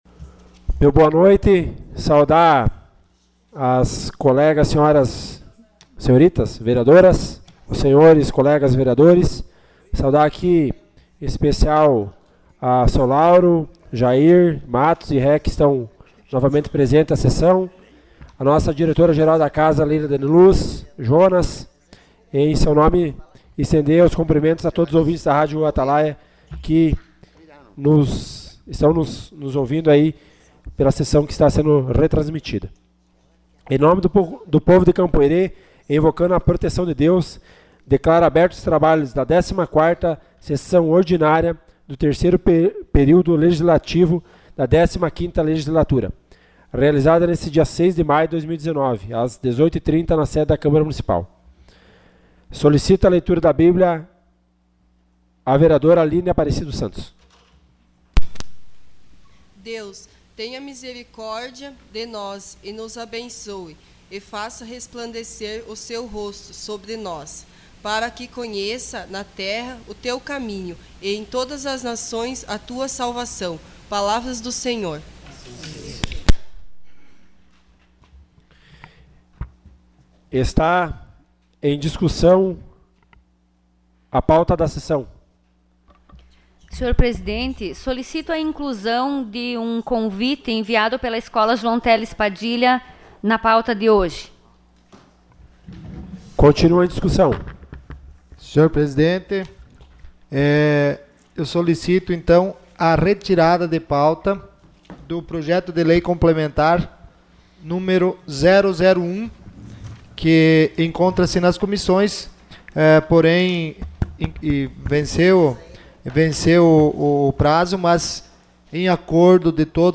Sessão Ordinária 06 de maio de 2019.